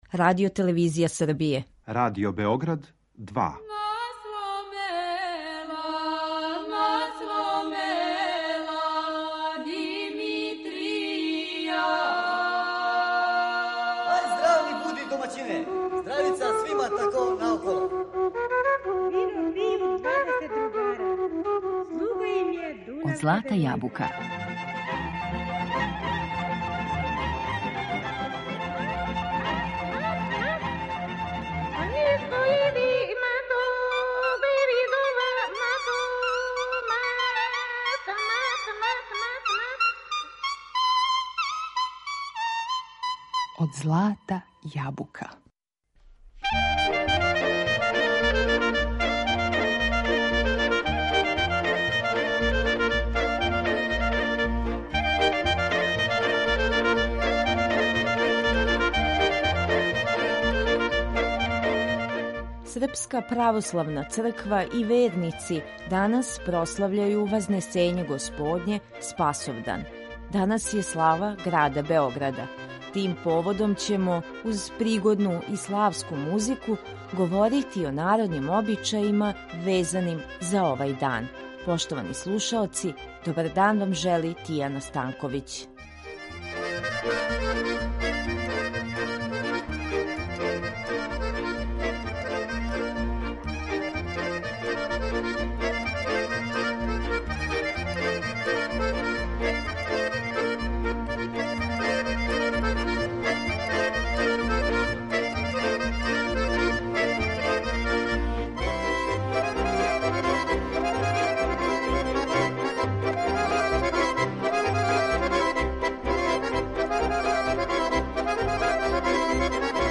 Тим поводом ћемо, у данашњем издању емисије Од злата јабука , уз пригодну и славску музику, говорити о народним обичајима везаним за овај дан.